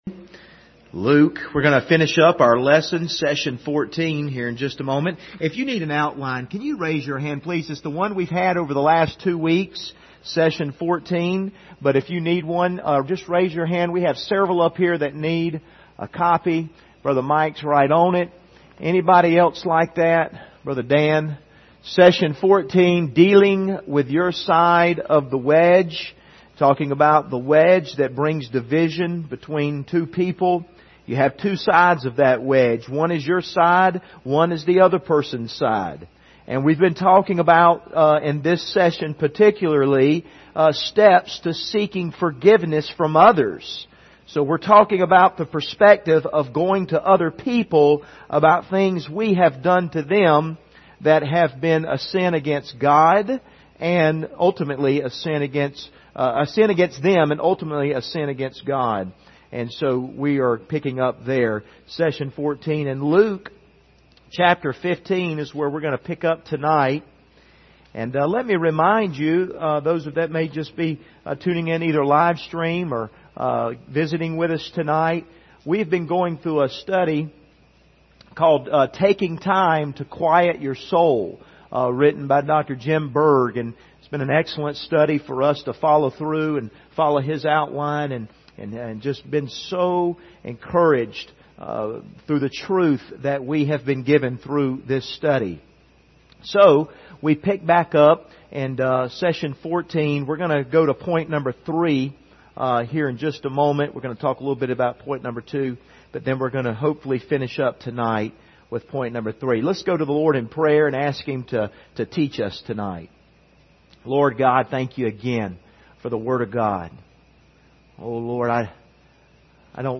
Service Type: Wednesday Evening Topics: relationships